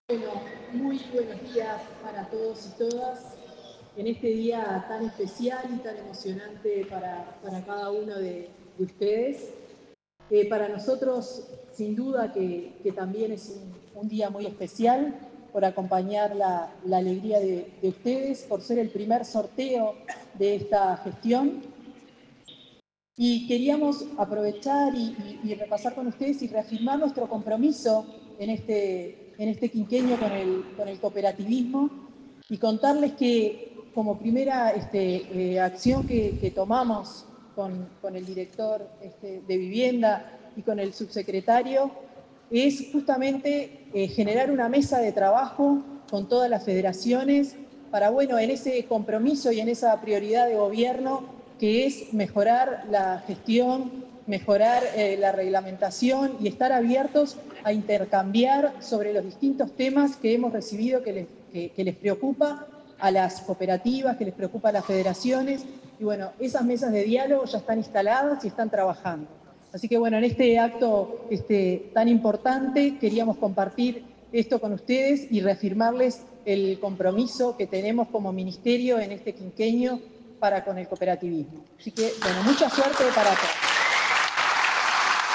Palabras de la ministra de Vivienda, Tamara Paseyro
La titular del Ministerio de Vivienda y Ordenamiento Territorial, Tamara Paseyro, se expresó durante el primer sorteo anual de cupos de esa secretaría